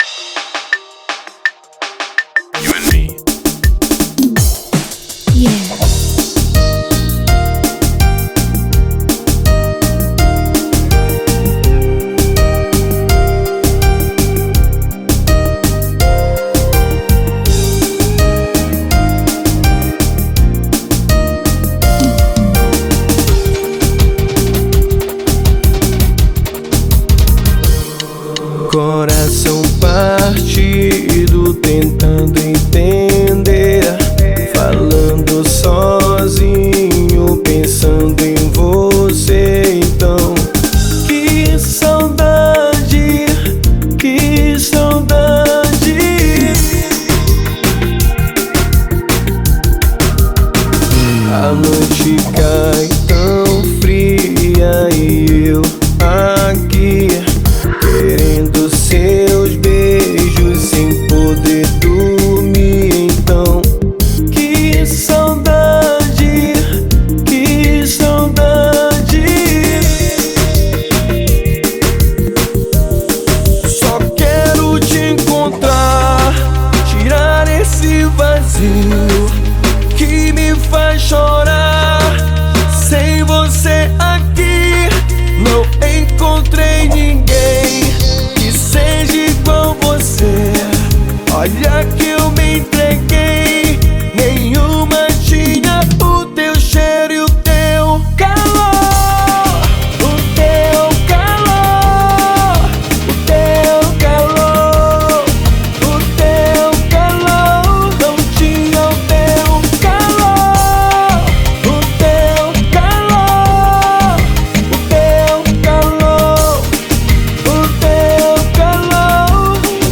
Melody